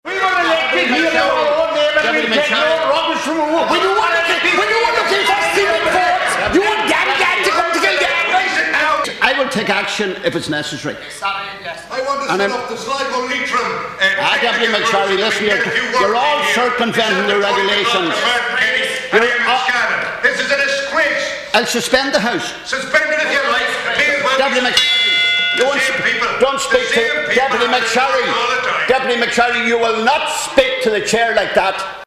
Fianna Fáil’s Marc McSharry, who represents Sligo, Leitrim and South Donegal took issue with Independent Michael Healy-Rae in the chamber.
As the row intensified, Leas-Cheann Comhairle Pat The Cope Gallagher suspended the Dáil…………